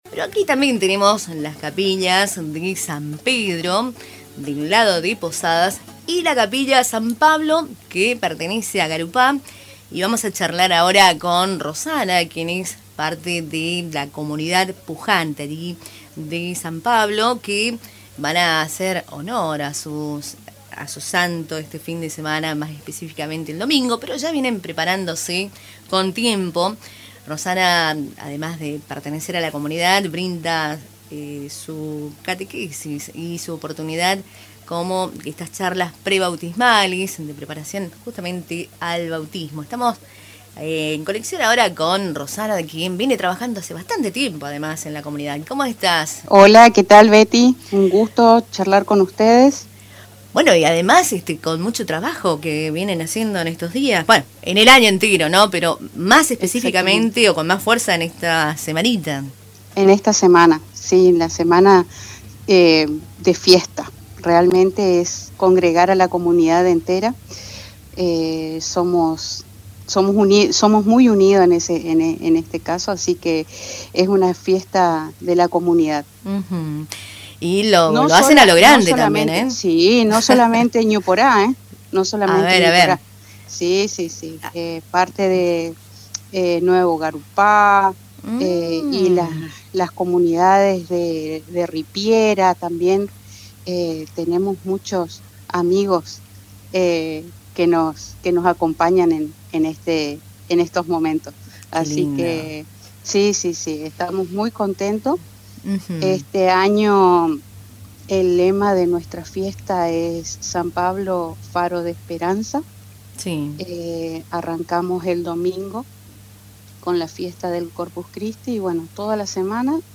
Entrevista completa en La FM 105.9